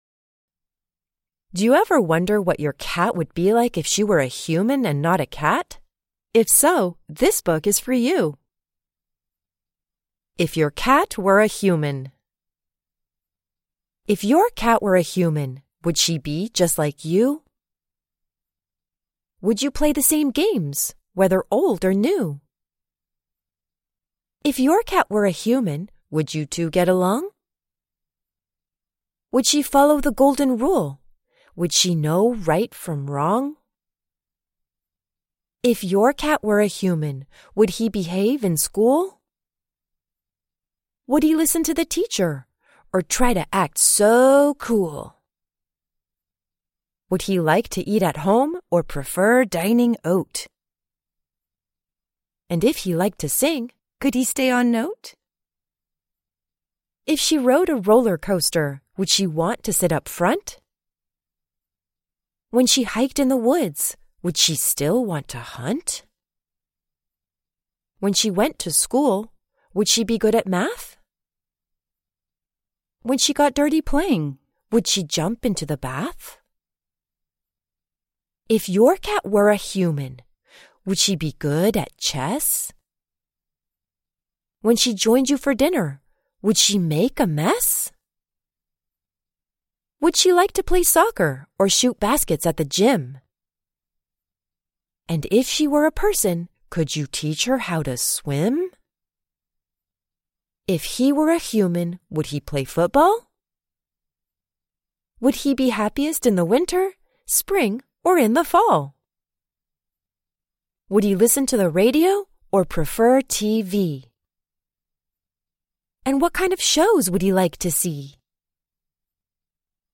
Reading IF YOUR CAT WERE A HUMAN